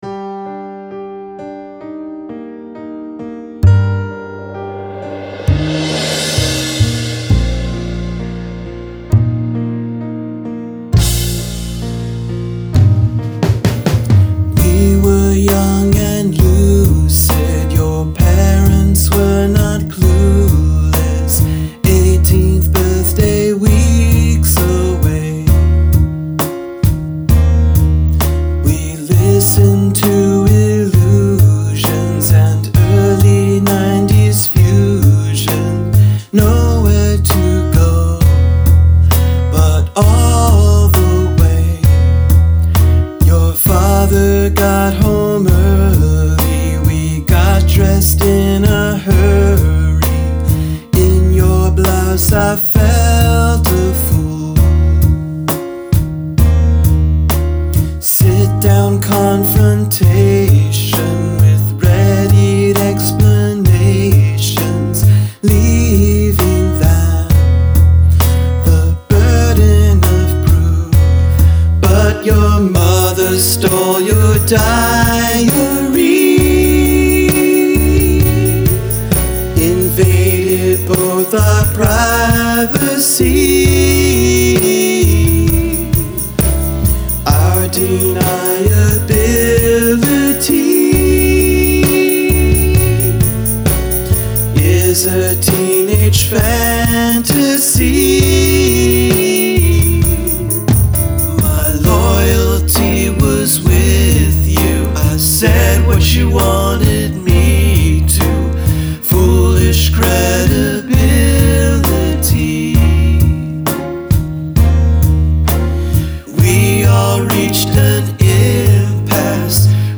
Vocal Harmony